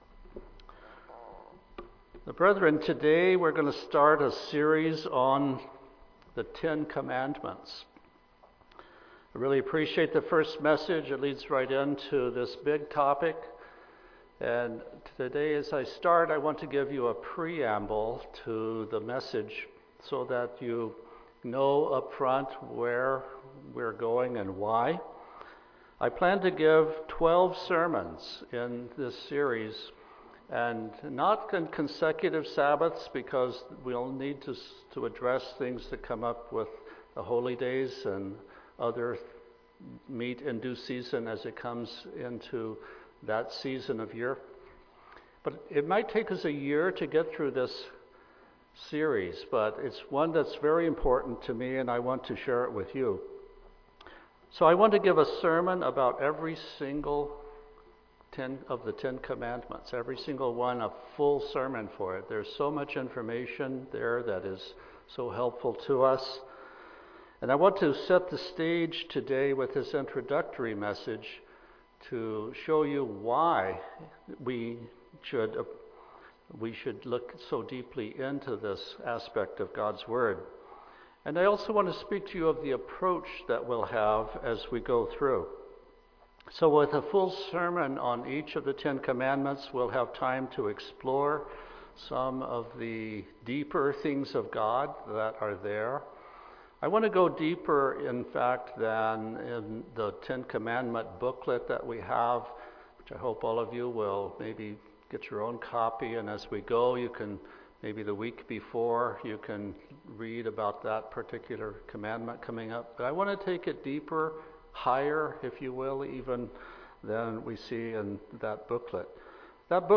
This is the first sermon in this series covering the Ten Commandments.
Given in Tacoma, WA